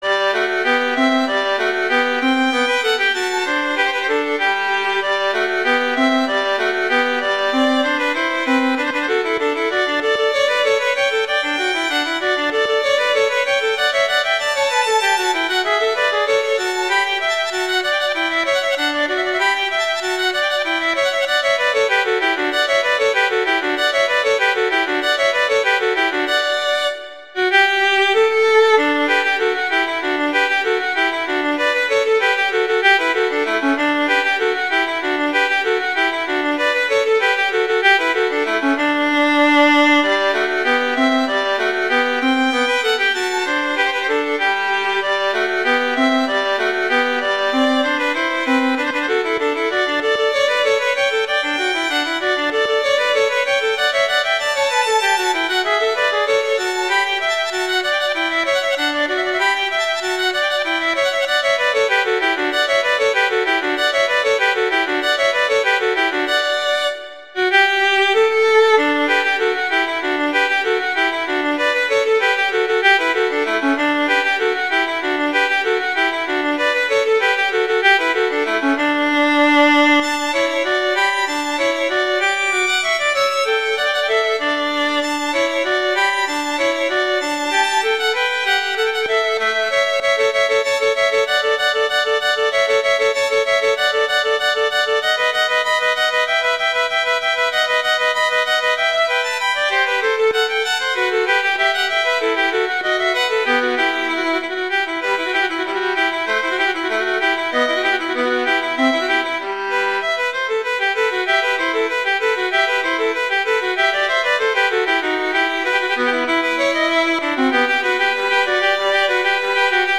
two violins
classical, french
G major